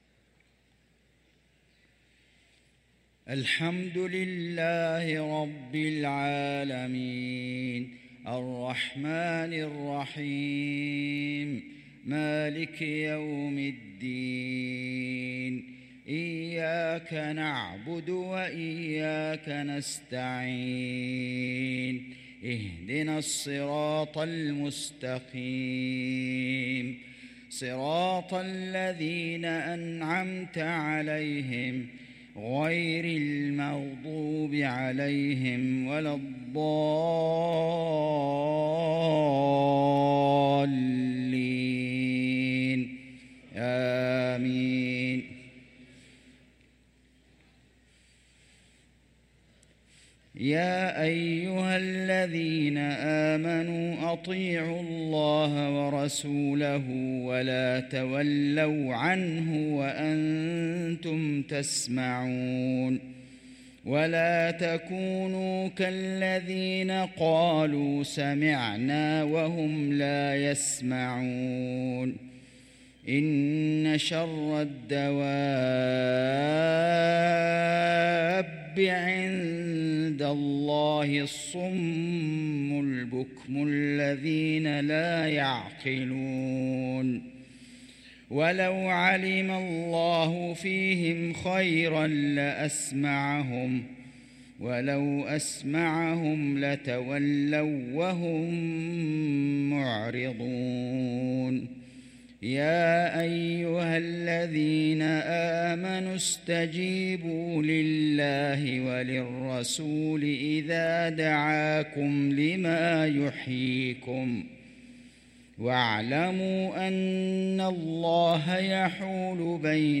صلاة العشاء للقارئ فيصل غزاوي 9 جمادي الأول 1445 هـ
تِلَاوَات الْحَرَمَيْن .